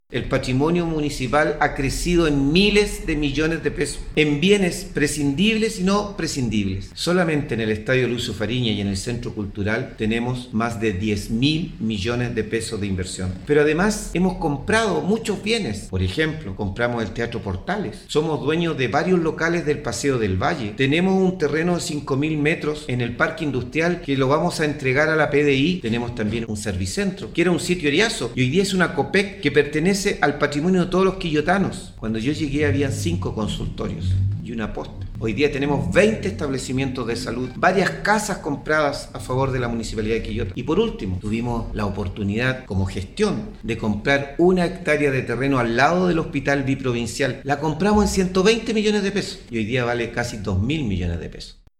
04-ALCALDE-MELLA-Aumento-del-patrimonio-municipal.mp3